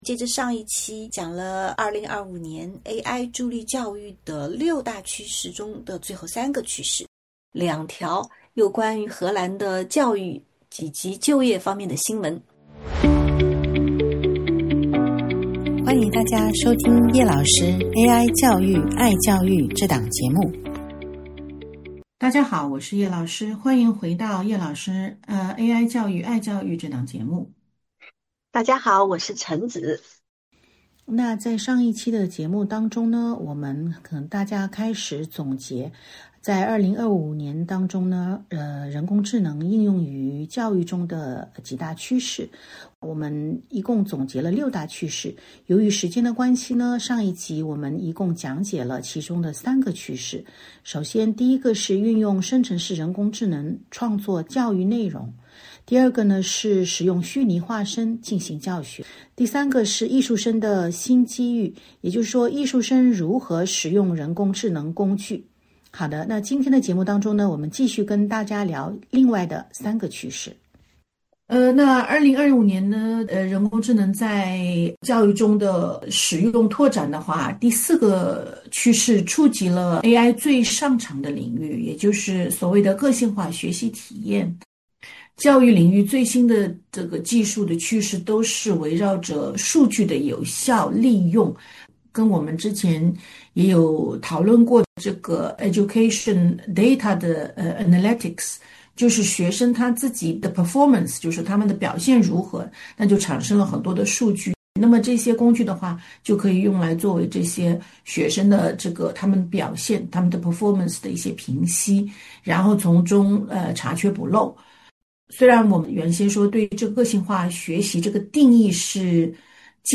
这一期两位主持人接着上一期，继续总结2025AI助力教育的六大趋势。